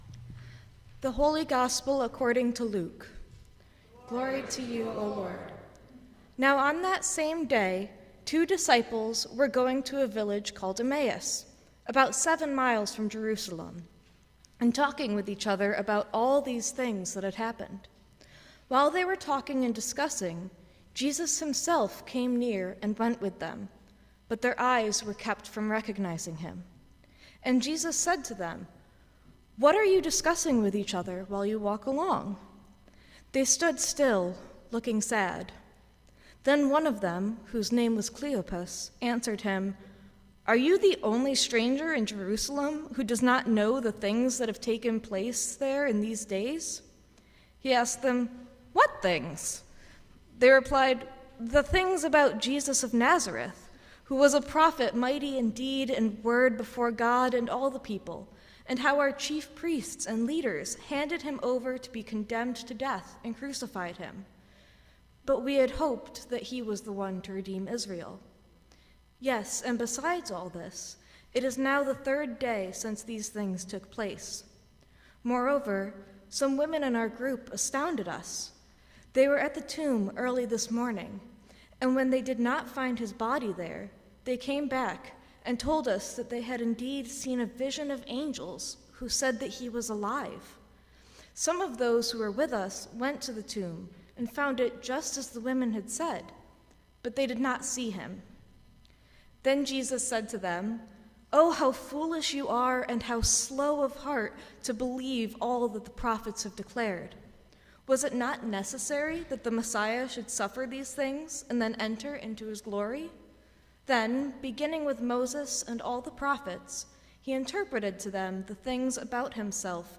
Sermon for the Third Sunday of Easter 2026